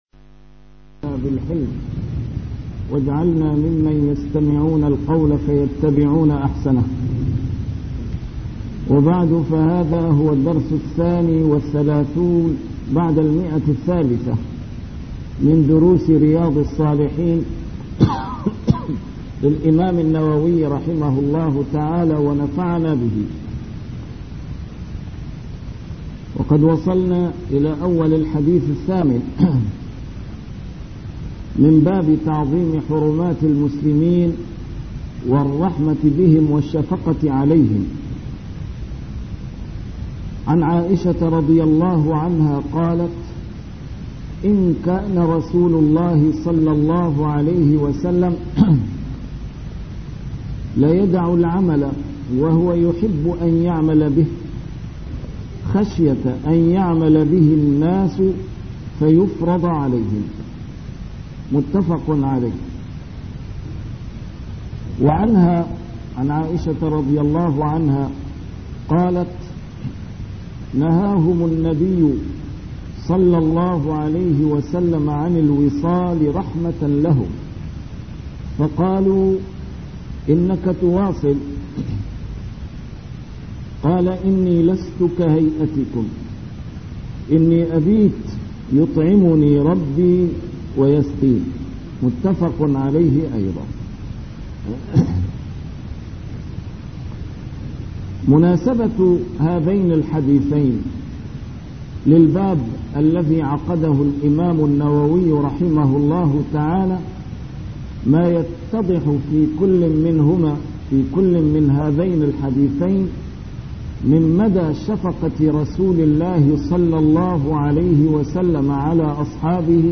A MARTYR SCHOLAR: IMAM MUHAMMAD SAEED RAMADAN AL-BOUTI - الدروس العلمية - شرح كتاب رياض الصالحين - 332- شرح رياض الصالحين: تعظيم حرمات المسلمين